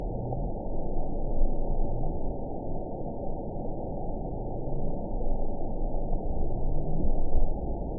event 919768 date 01/22/24 time 14:28:44 GMT (1 year, 3 months ago) score 5.56 location TSS-AB07 detected by nrw target species NRW annotations +NRW Spectrogram: Frequency (kHz) vs. Time (s) audio not available .wav